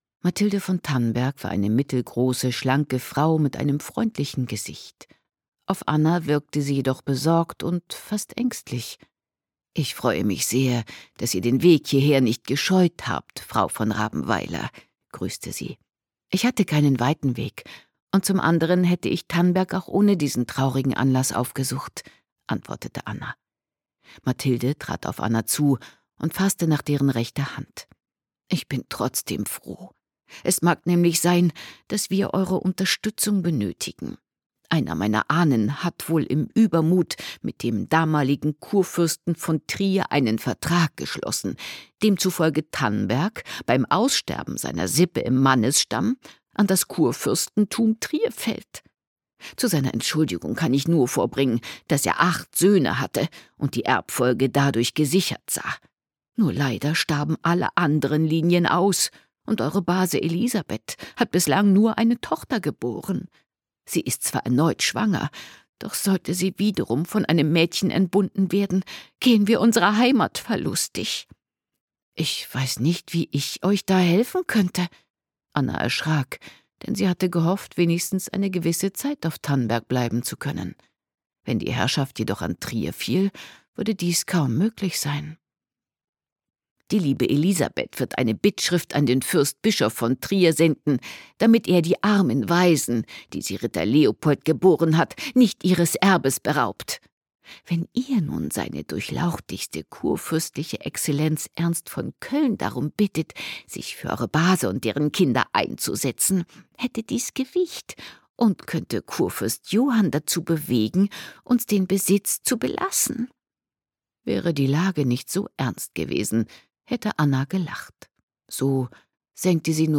Gekürzt Autorisierte, d.h. von Autor:innen und / oder Verlagen freigegebene, bearbeitete Fassung.